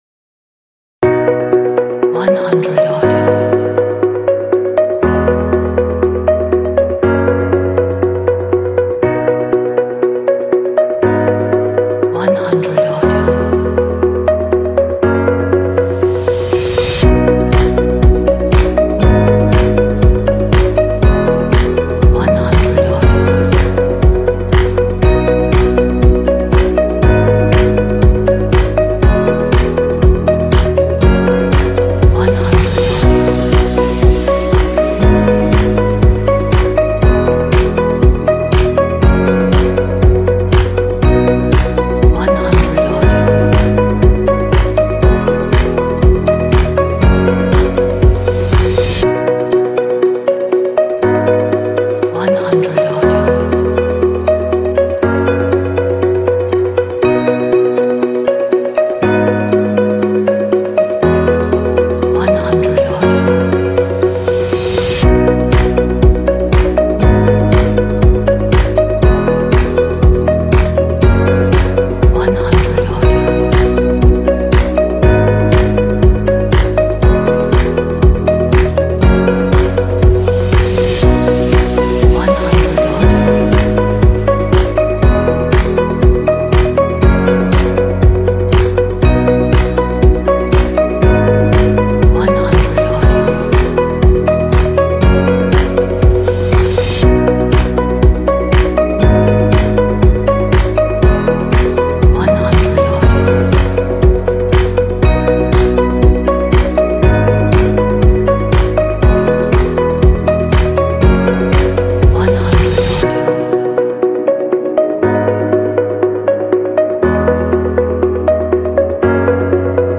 Technology Indie Corporate track for your projects.